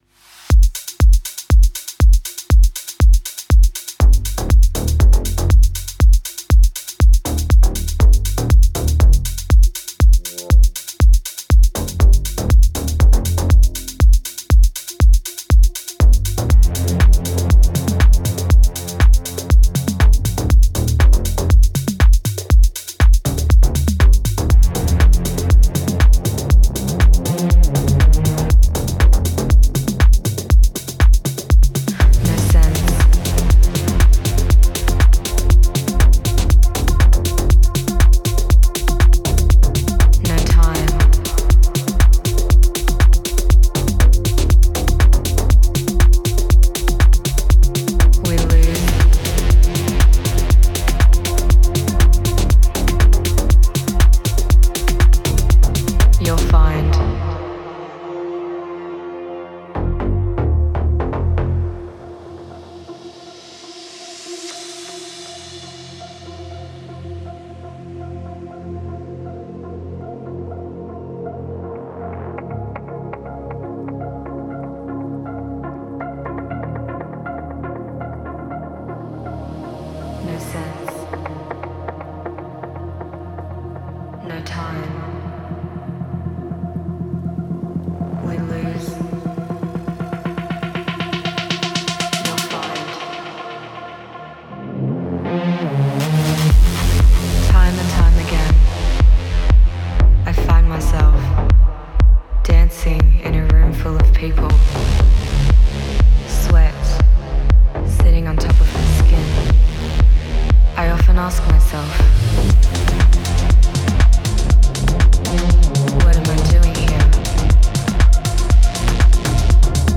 melodic techno journey through inescapable realities.